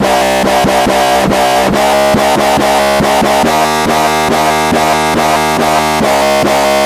奇怪的杜比斯特普循环2
140 bpm。